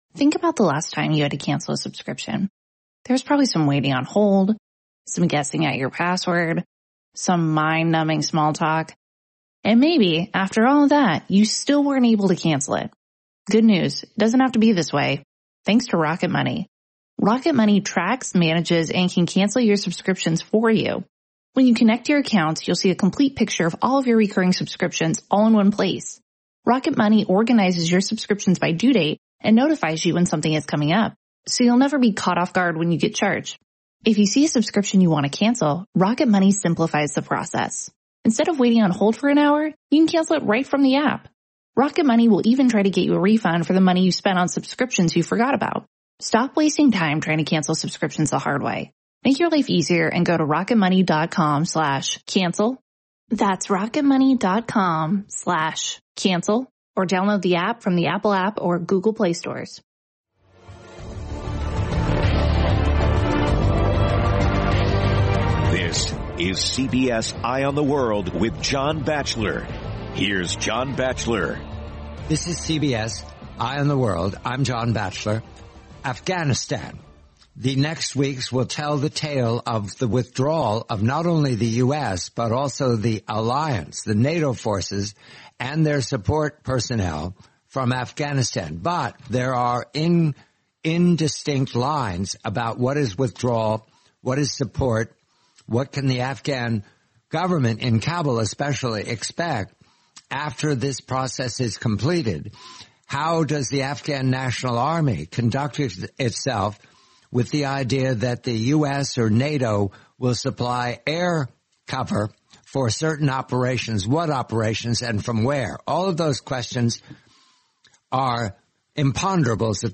the complete, nineteen-minute interview